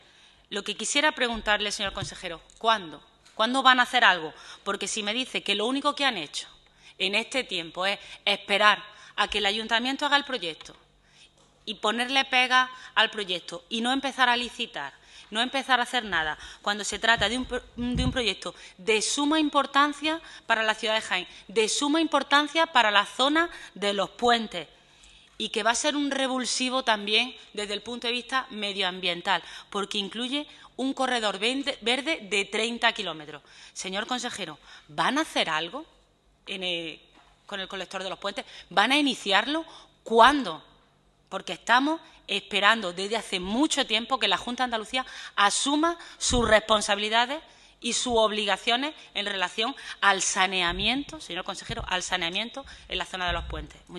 En la Comisión de Agua del Parlamento andaluz, la parlamentaria socialista cuestionó al consejero “cuándo van a iniciar” la obra, porque “ estamos esperando desde hace mucho tiempo que la Junta asuma su responsabilidad y sus obligaciones respecto al saneamiento en la zona de los Puentes”.